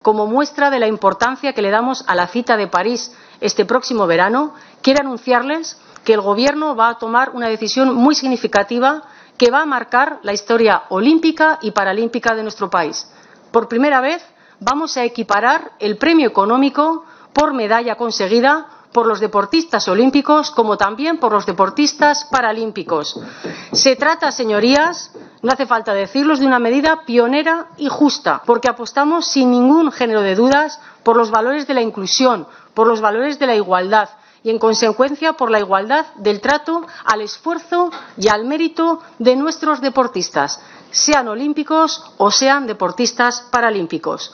Así lo anunció la ministra de Educación, Formación Profesional y Deportes, Pilar Alegría, el pasado 14 de marzo durante su comparecencia en la Comisión de Educación, Formación Profesional y Deportes del Senado para informar sobre las prioridades de su Departamento en esta legislatura, resaltando que se trata de una decisión  “muy significativa” y “va a marcar la historia olímpica y paralímpica de nuestro país”